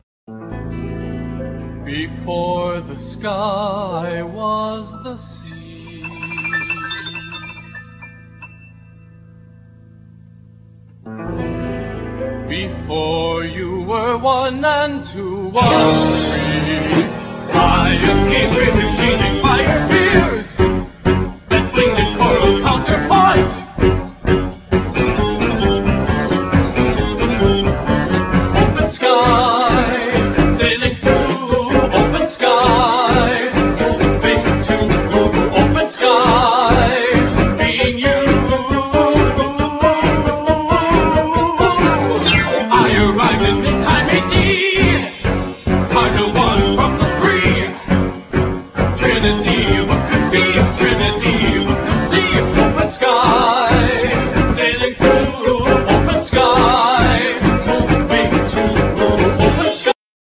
drums
tenor sax
trumpet
alto sax
bass guitar